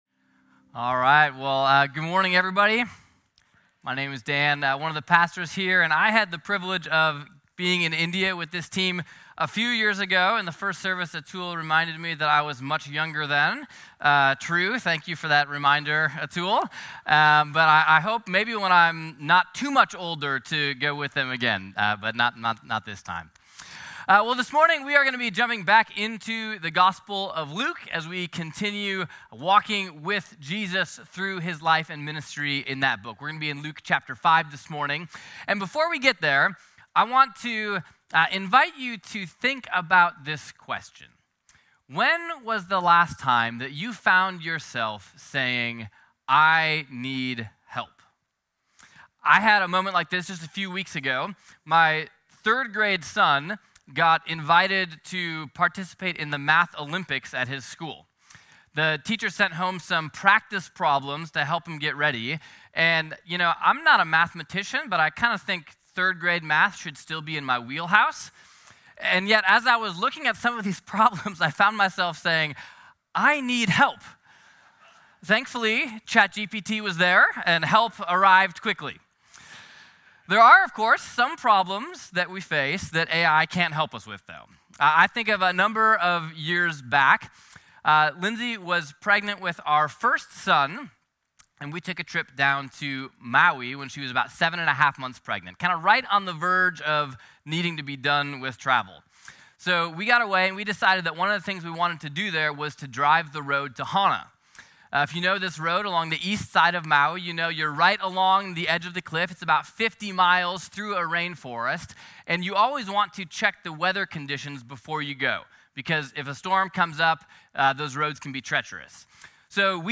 A message from the series "Luke." Amid the cacophony of voices that inundate our ears, how do we hear from God?